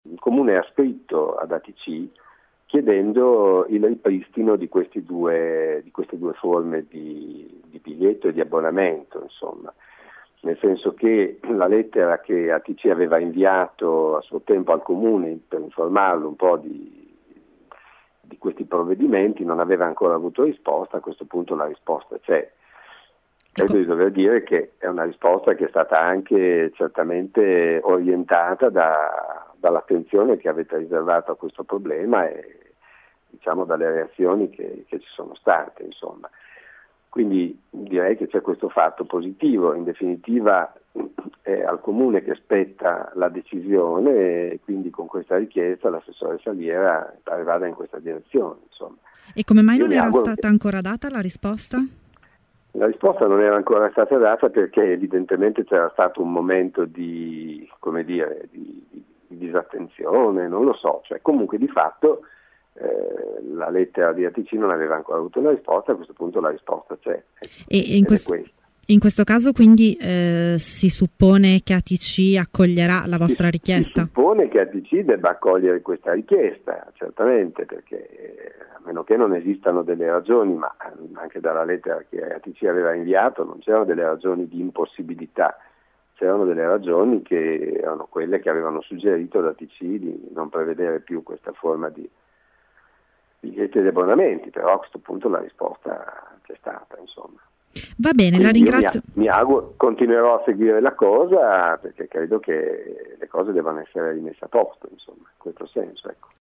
Per il consigliere del Pd, quella del Comune è una risposta che «è stata orientata dall’attenzione che avete dato al problema», ha detto ai nostri microfoni